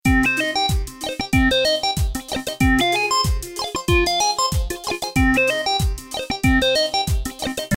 复古音乐 游戏背景音乐 电子音乐 芯片音乐